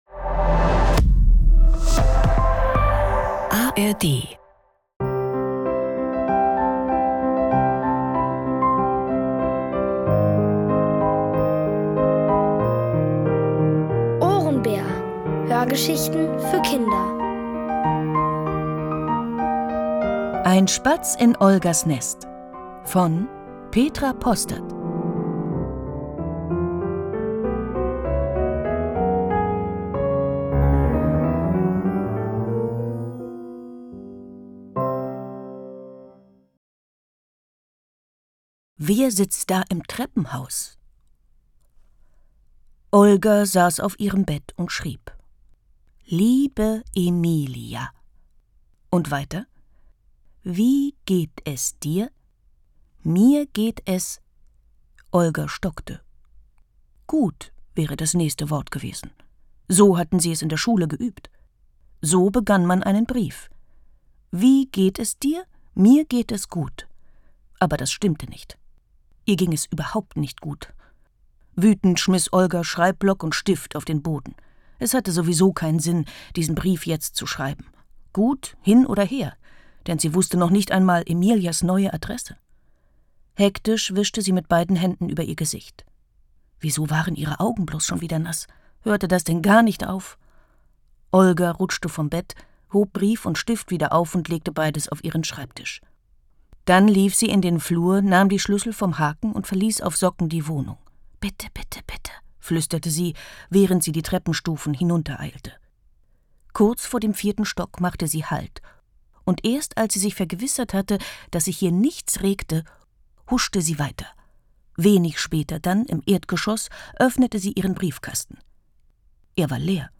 Alle 6 Folgen der OHRENBÄR-Hörgeschichte: Ein Spatz in Olgas Nest von Petra Postert.